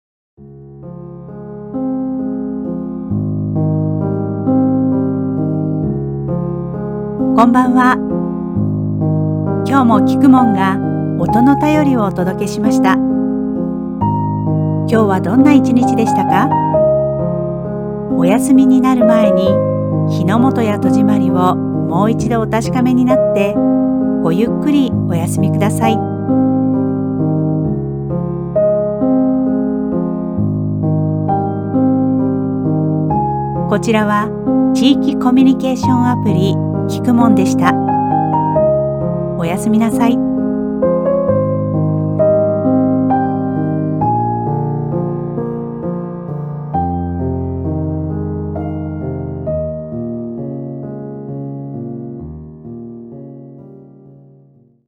本日もおやすみなさい [その他のファイル／10.05MB] 録音音声